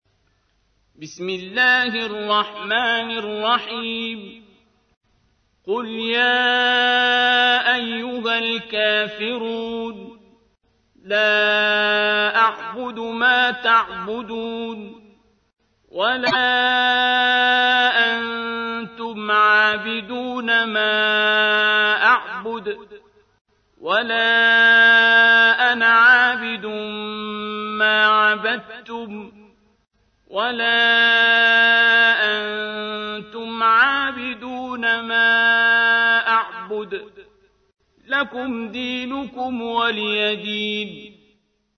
تحميل : 109. سورة الكافرون / القارئ عبد الباسط عبد الصمد / القرآن الكريم / موقع يا حسين